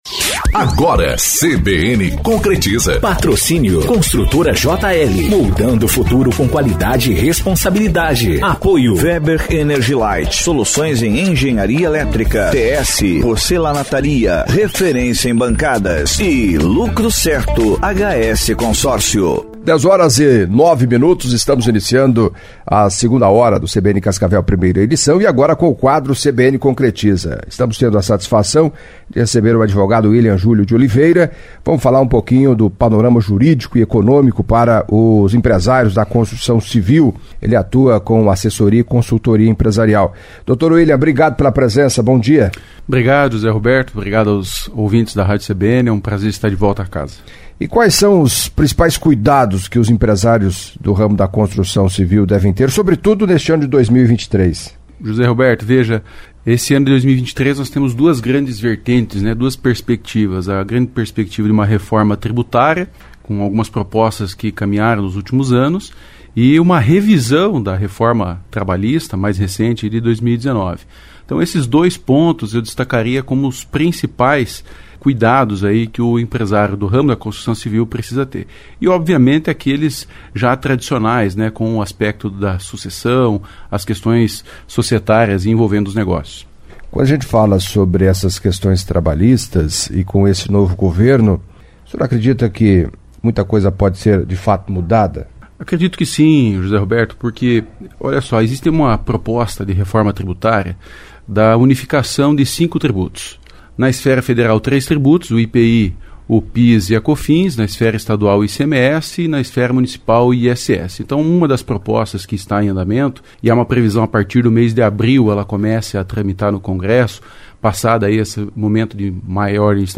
Editoriais